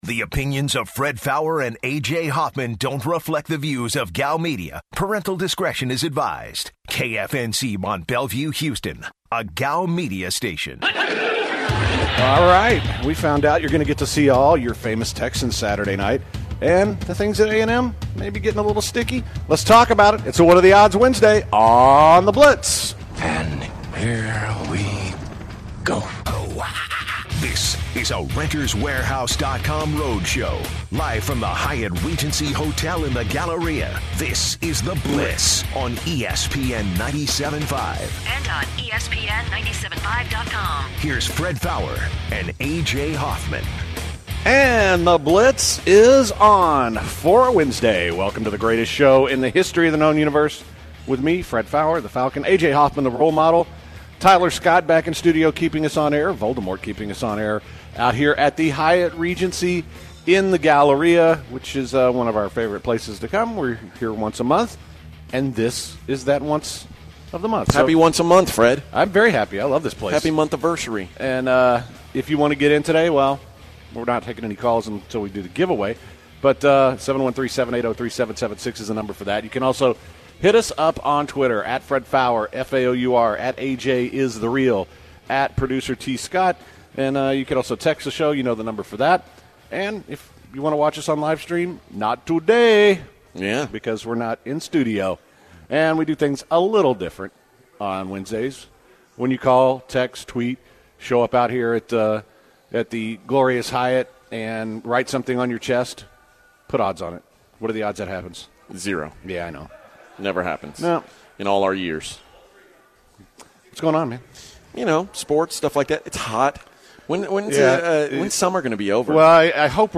start the show on location at the Urban Star Bar